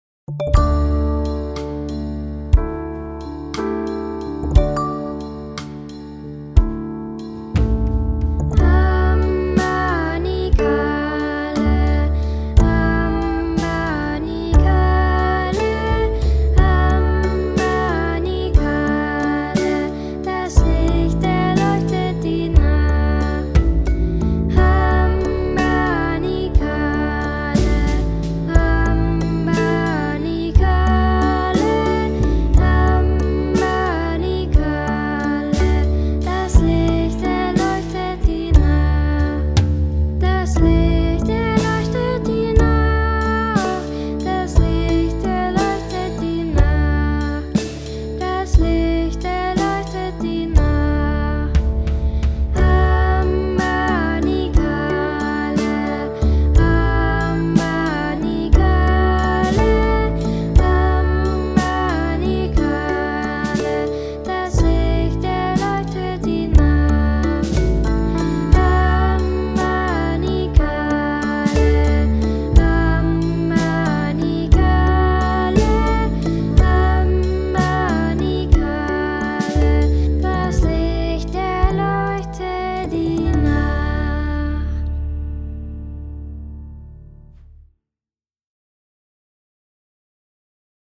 Liedeinspielung HAMBANI KAHLE
Hambanikahle_voc.mp3